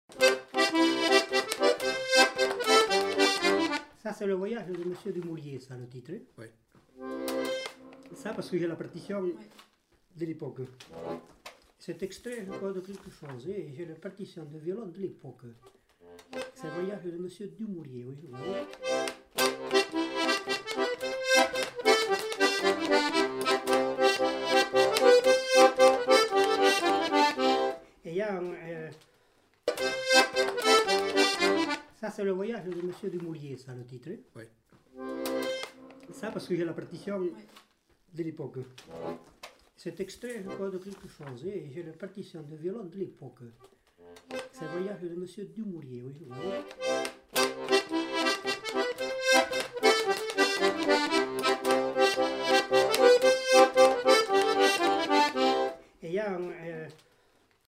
Aire culturelle : Lomagne
Lieu : Pessan
Genre : morceau instrumental
Instrument de musique : accordéon chromatique
Danse : rondeau